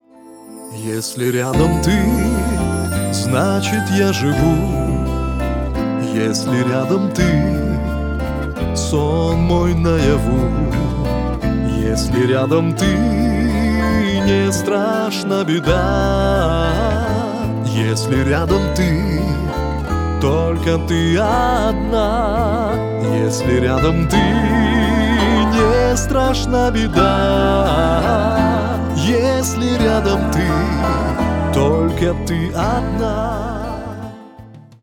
Шансон
спокойные